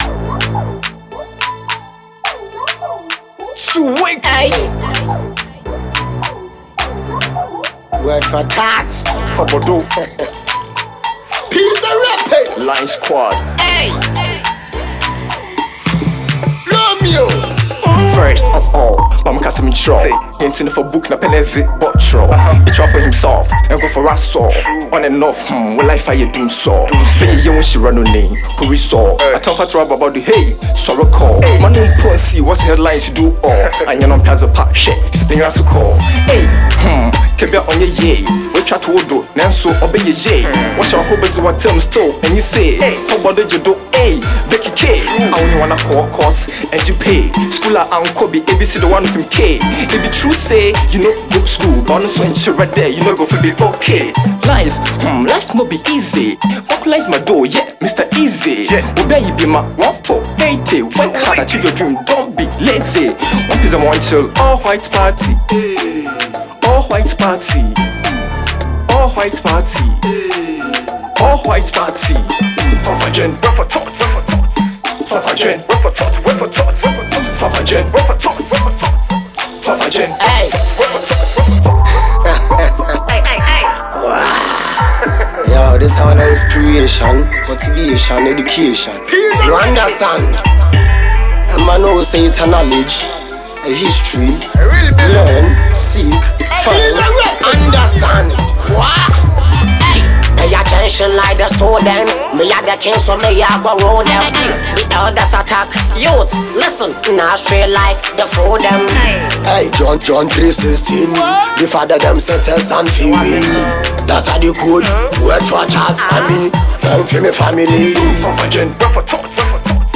rapper
inspirational song
dancehall